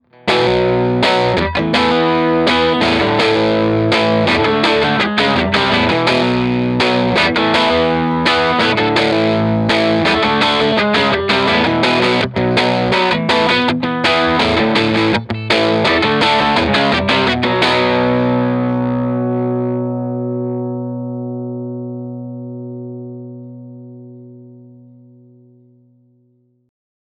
18 Watt v6 - 6V6 Dirty Tone Tubby Alnico
Note: We recorded dirty 18W tones using both the EL84 and 6V6 output tubes.
Keep in mind when listening that the tones I dialed in were "average" tones.
18W_DIRTY_6V6_ToneTubbyAlnico.mp3